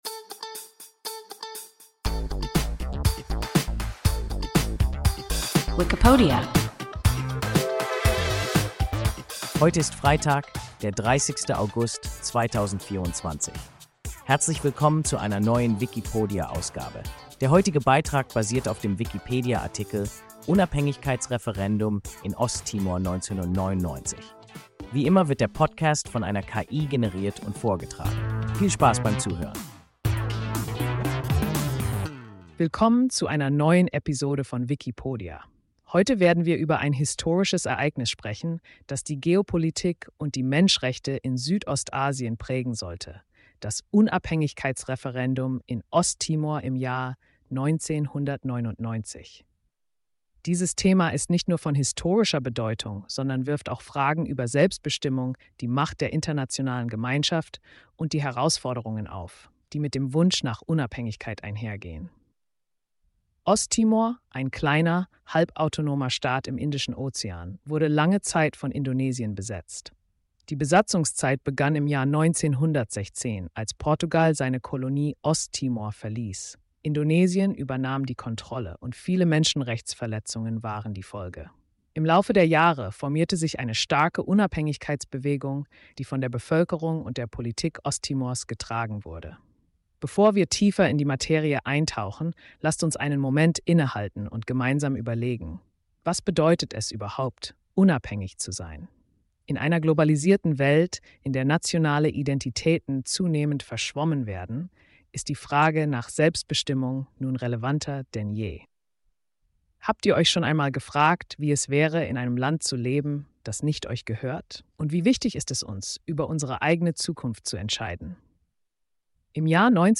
Unabhängigkeitsreferendum in Osttimor 1999 – WIKIPODIA – ein KI Podcast